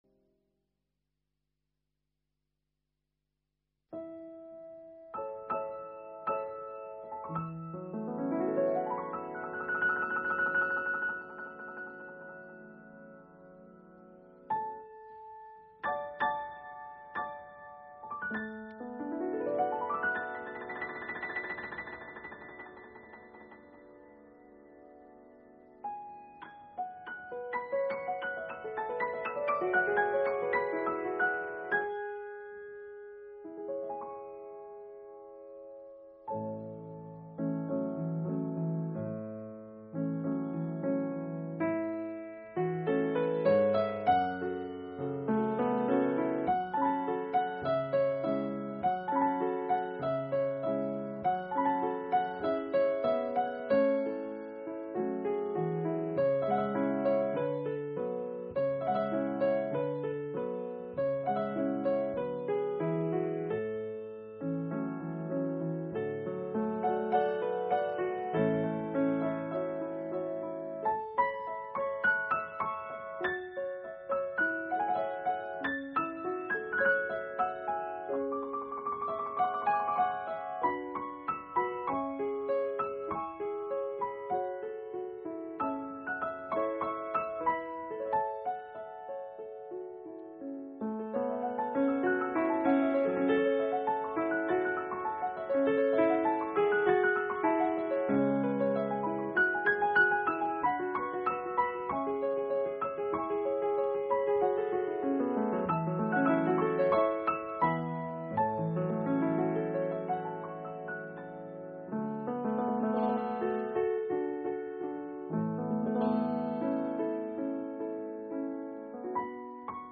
委婉质朴的旋 律和流畅多变的节奏，加之丝丝入扣的演奏
HDCD型处理，比普通唱片更具有清晰感、真实感、定位感。